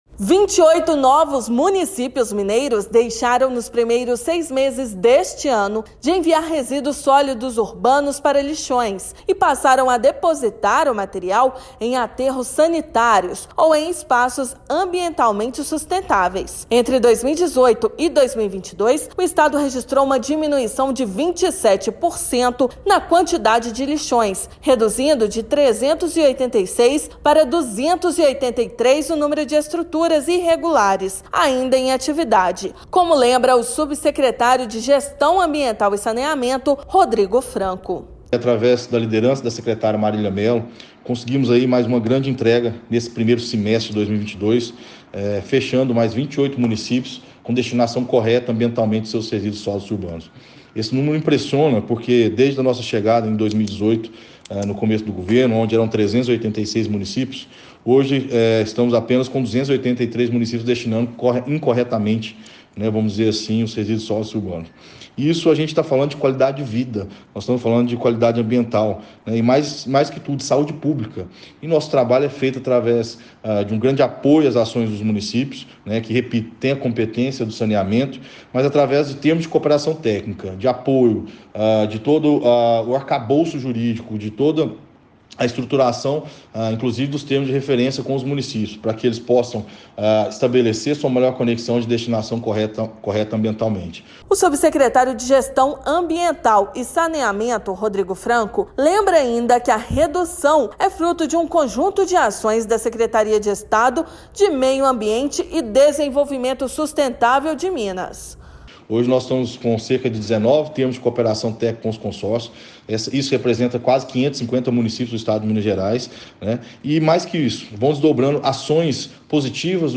Regularização no destino de resíduos sólidos e encerramento de lixões já é realidade em 450 cidades mineiras. Ouça matéria de rádio.